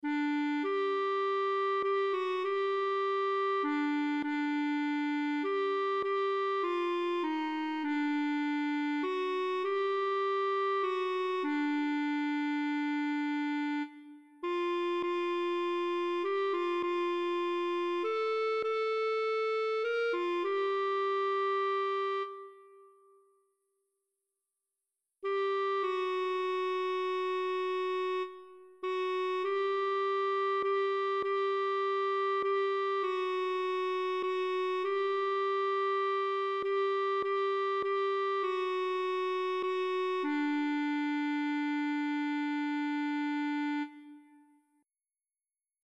Partitura, cor mixt (pdf): Din cer senin
Voci (mp3): sopran, alto, tenor, bas, cor mixt